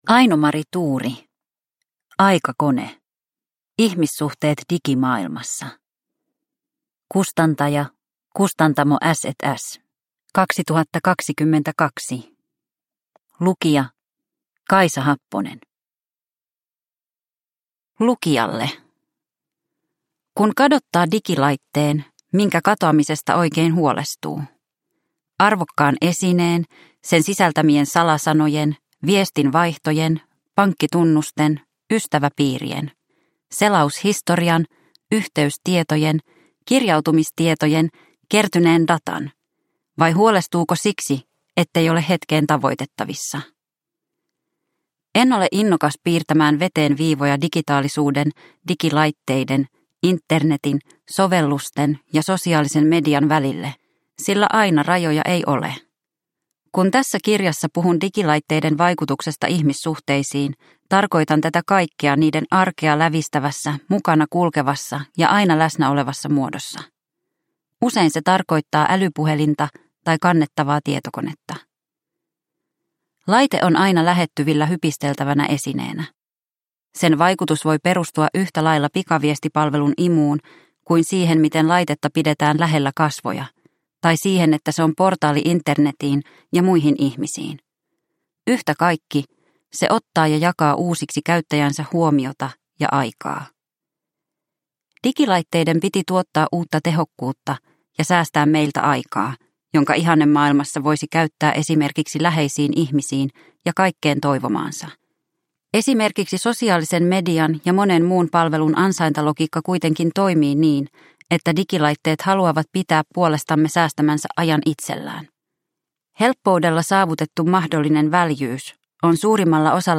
Aikakone – Ljudbok – Laddas ner